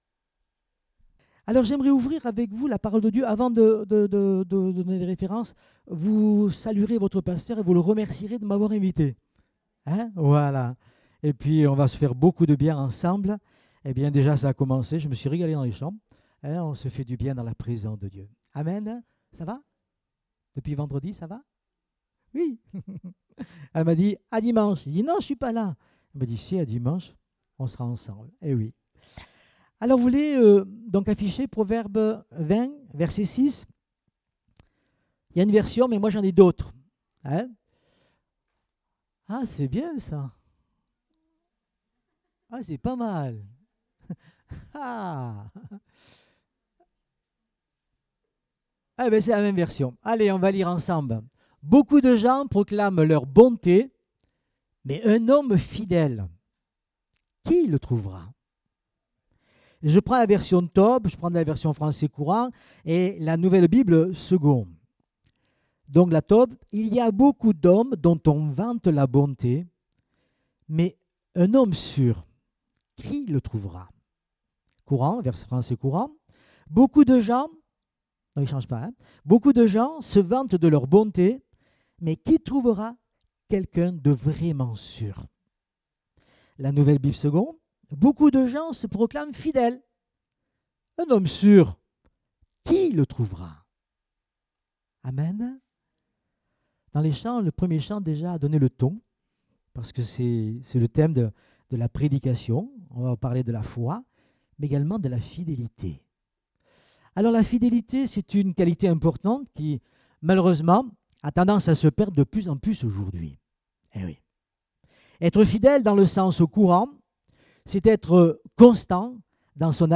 Date : 26 août 2018 (Culte Dominical)